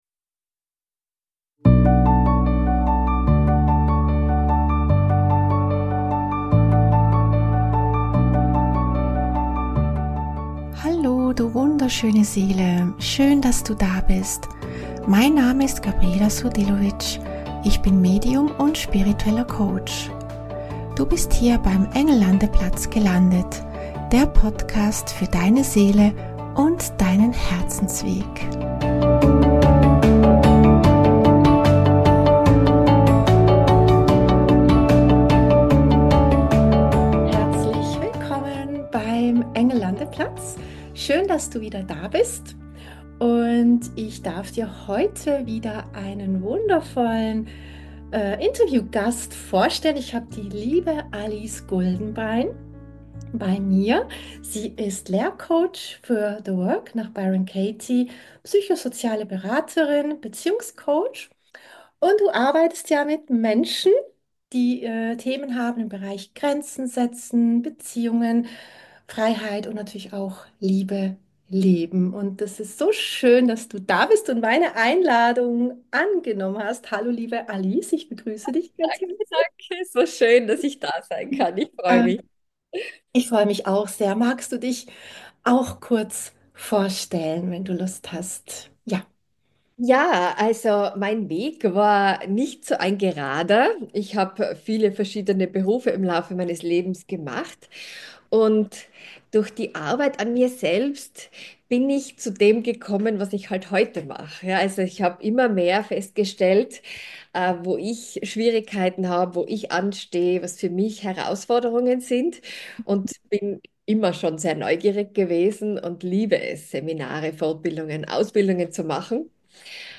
Beschreibung vor 11 Monaten In dieser Folge habe ich wieder einmal einen Gast zu Besuch beim Engel Landeplatz.